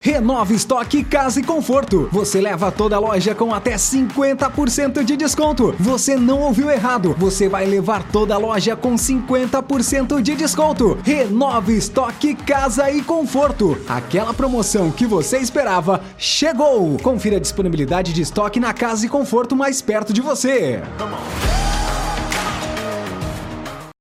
Varejo: